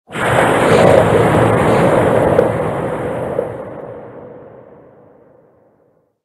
Cri de Dunaconda Gigamax dans Pokémon HOME.
Cri_0844_Gigamax_HOME.ogg